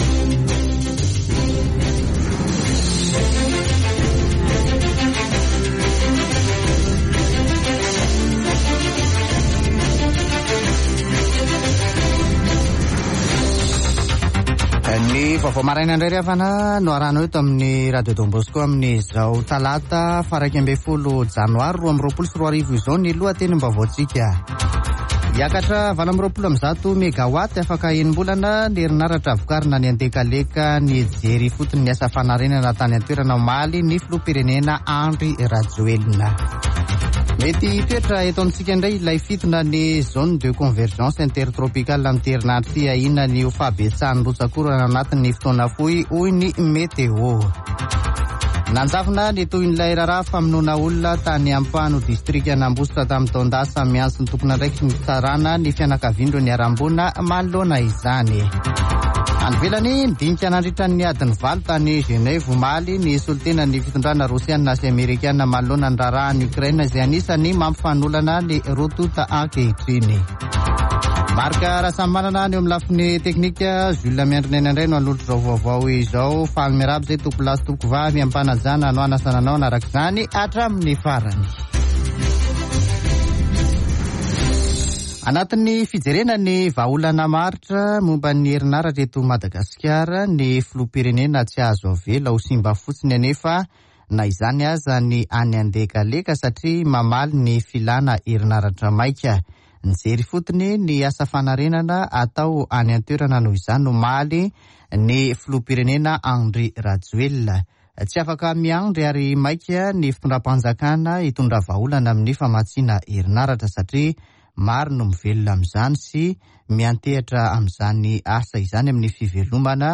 [Vaovao maraina] Talata 11 janoary 2022